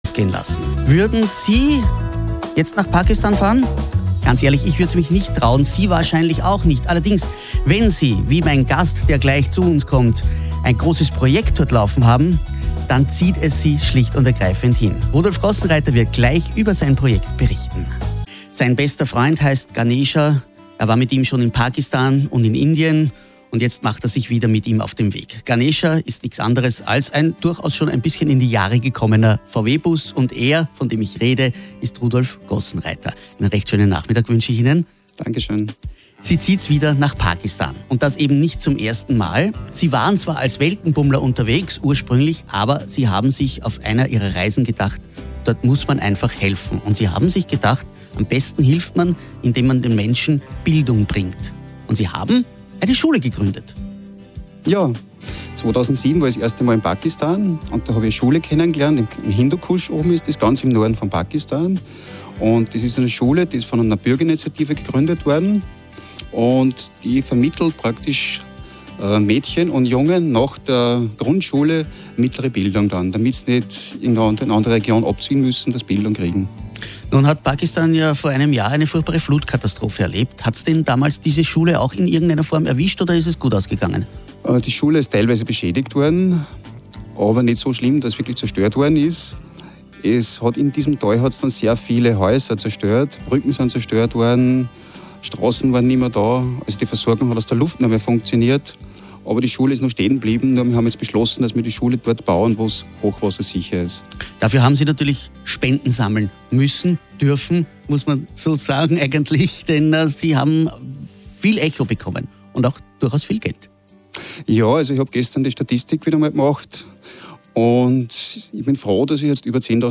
Radio OÖ 11-5-28:  Interview